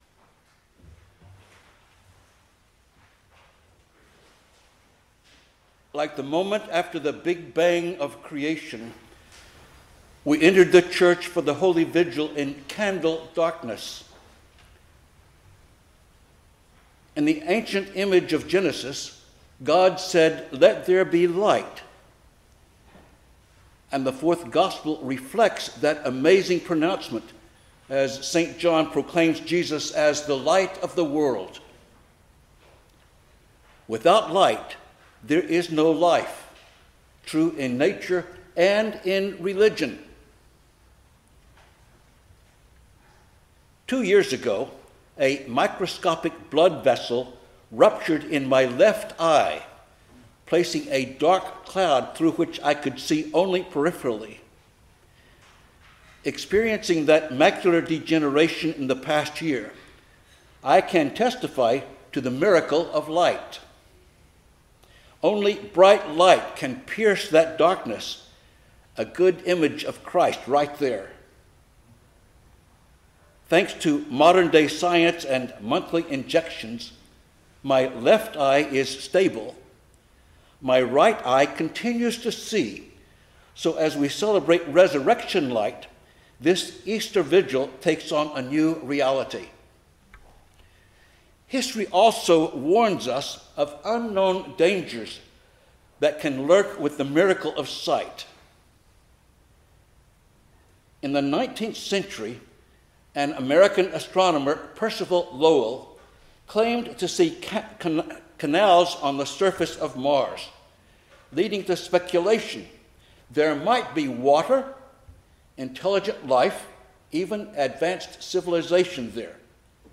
The Great Vigil of Easter